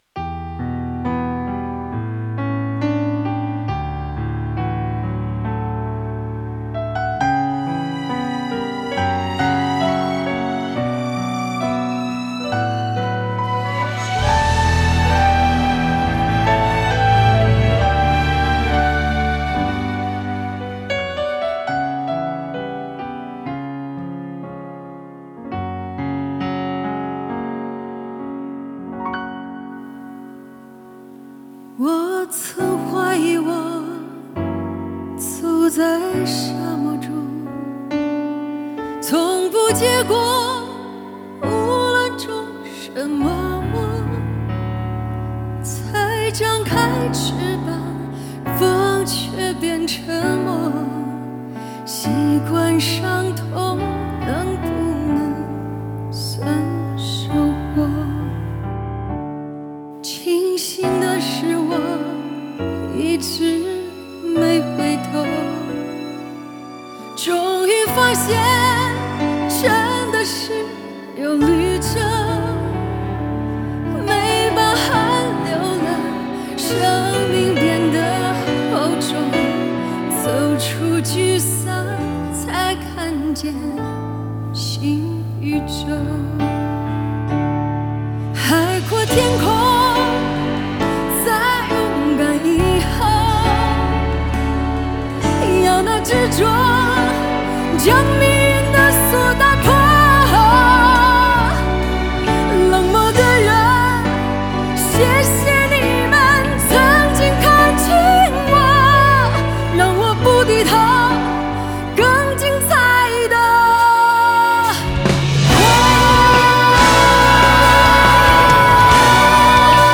Ps：在线试听为压缩音质节选，体验无损音质请下载完整版
吉他
Bass
打击乐
鼓手
和声
萨克斯/长笛
长号
小号